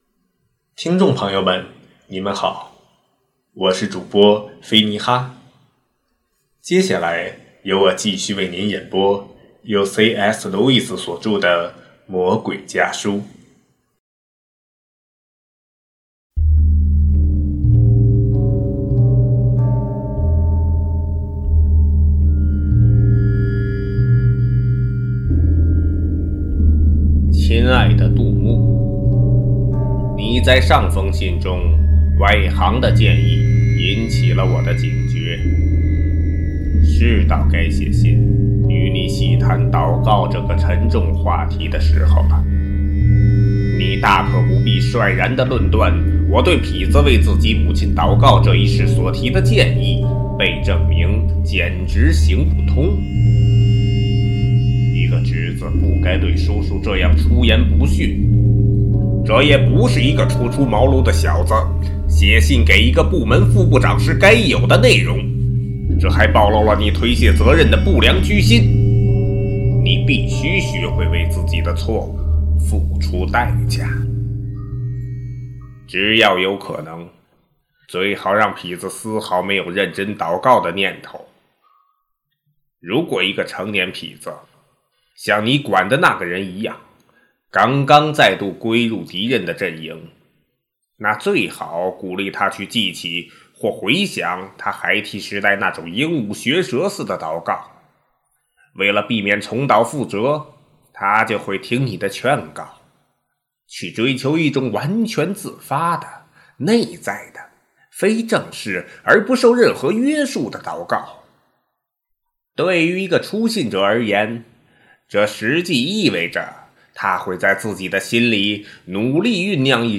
首页 > 有声书 | 灵性生活 | 魔鬼家书 > 魔鬼家书：第四封书信